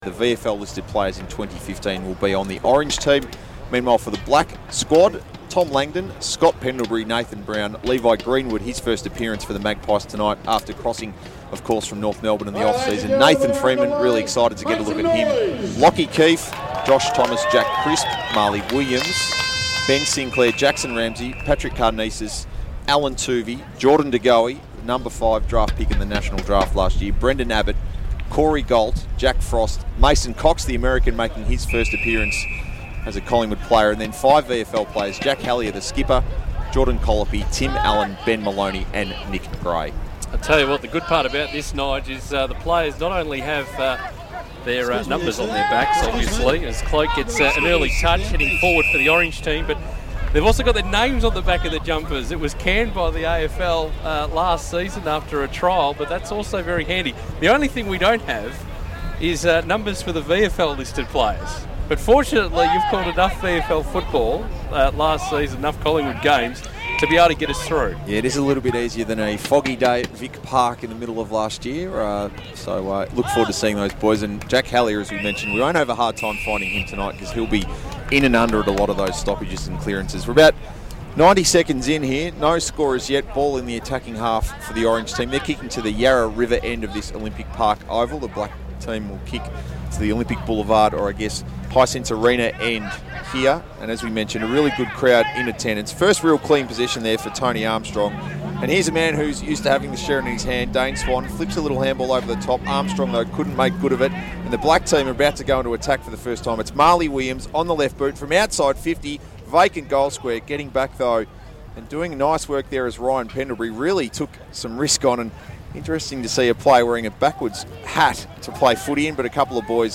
Listen to Collingwood Radio's commentary of the Magpies' first intra-club practice match of 2015 on Thursday 19 February.